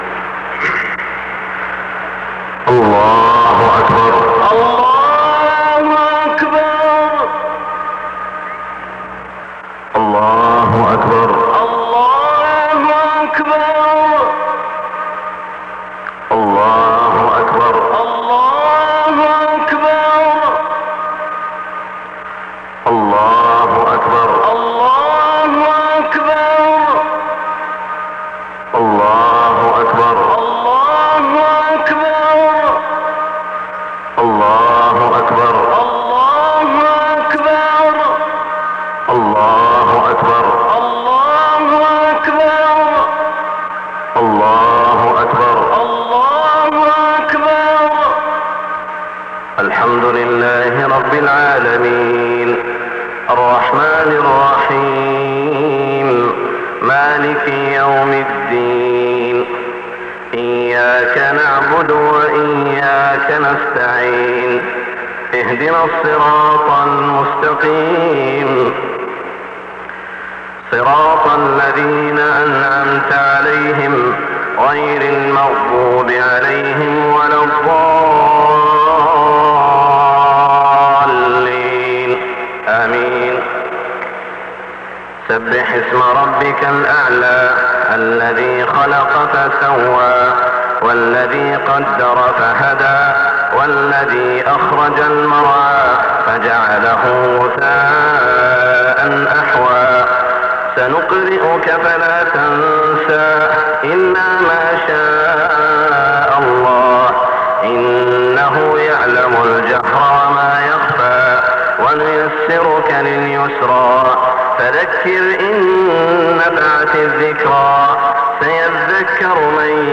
صلاة الاستسقاء 6-1-1425 سورتي الأعلى و الغاشية > 1425 🕋 > الفروض - تلاوات الحرمين